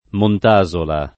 vai all'elenco alfabetico delle voci ingrandisci il carattere 100% rimpicciolisci il carattere stampa invia tramite posta elettronica codividi su Facebook Montasola [ mont #@ ola ] top. (Lazio) — paese nei monti della Sabina